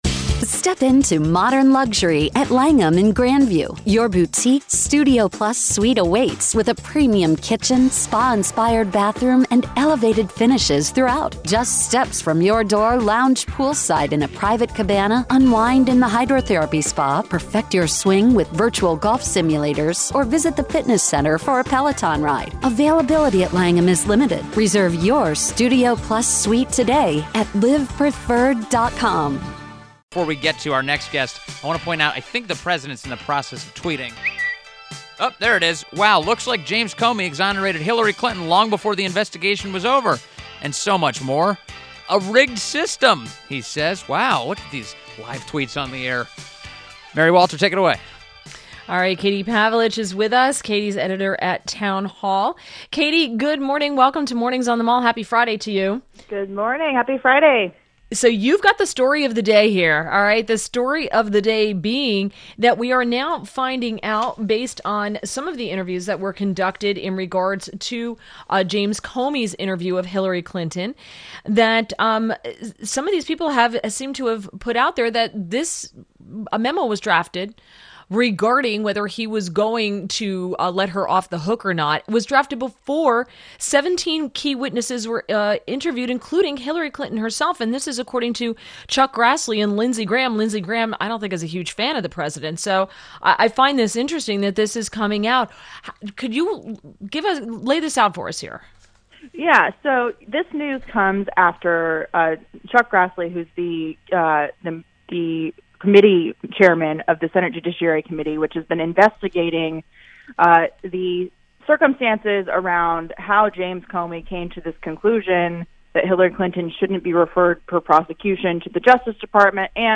WMAL Interview - KATIE PAVLICH - 09.01.17